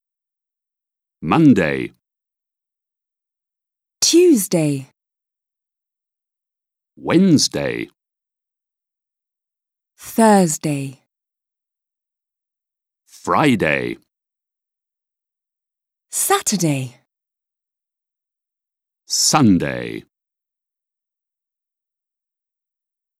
• Écoute et répétition : prononciation des jours
2. Listen and repeat.
Introduction_sequence-days-of-the-week.wav